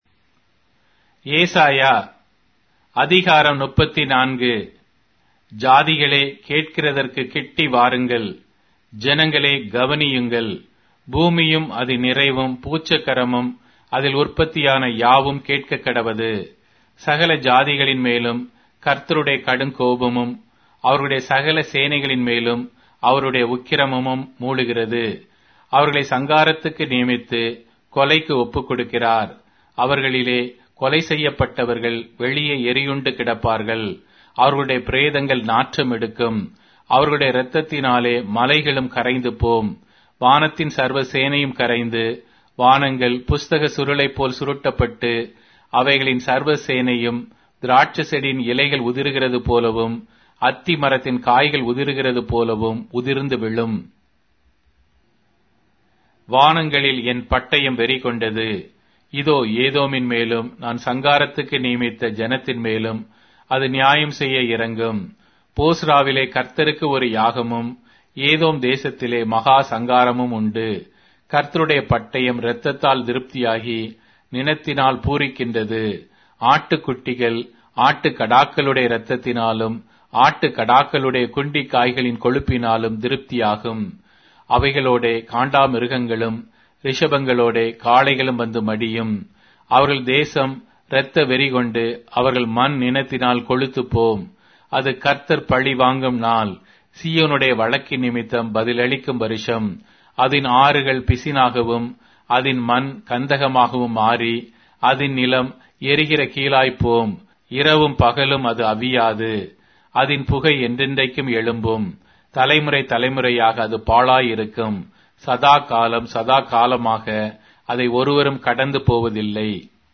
Tamil Audio Bible - Isaiah 57 in Orv bible version